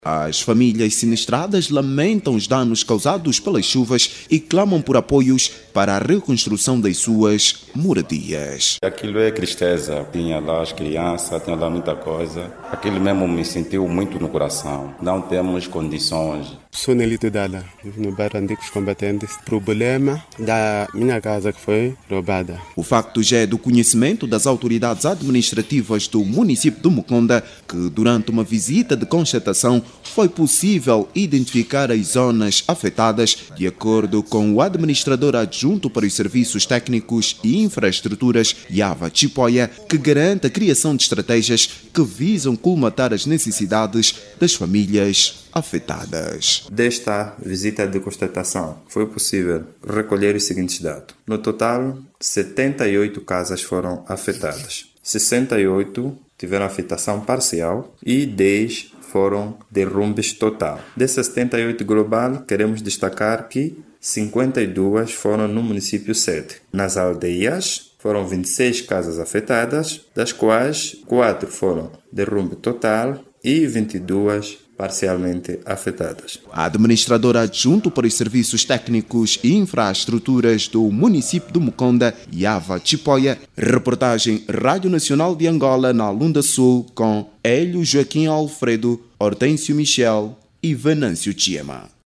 Muitas famílias do município do Muconda ficaram sem as suas habitações devido às fortes chuvas. Jornalista